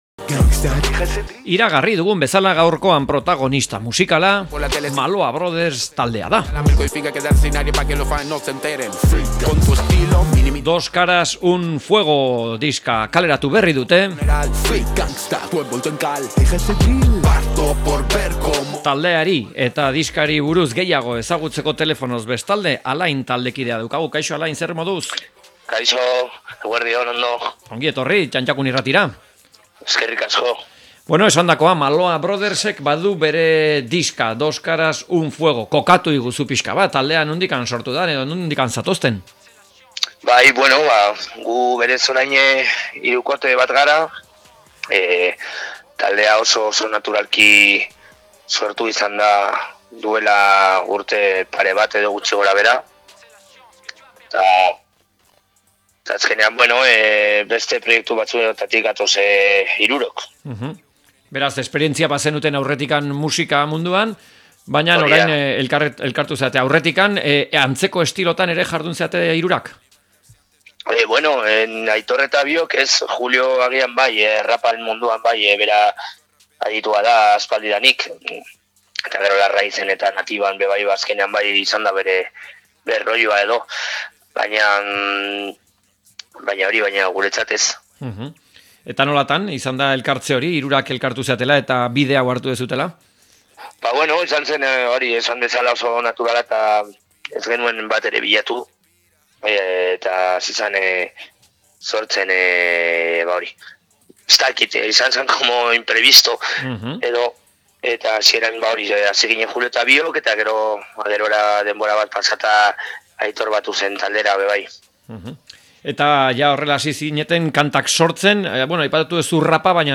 Maloa Brothers-i elkarrizketa